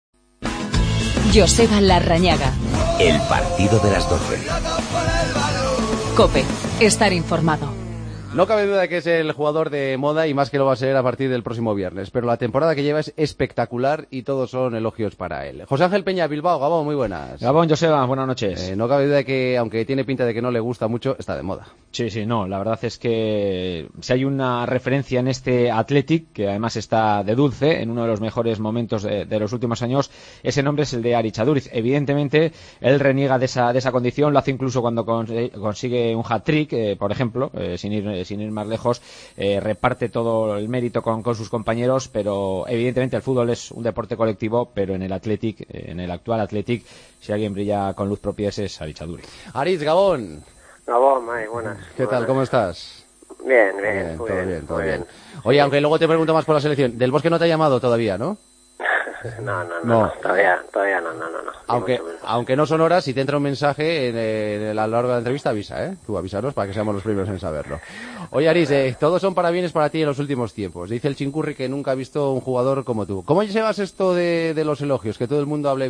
Entrevista a Aritz Aduriz, que este viernes podría ser llamado por Vicente del Bosque. Entrevista a Juanfran, autor del último penalti que ha dado la clasificación al Atleti.